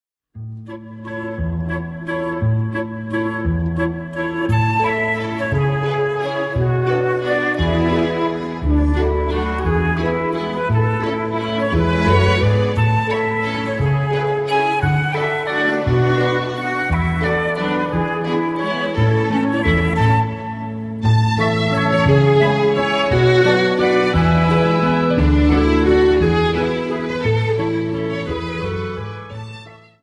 Dance: Viennese Waltz 59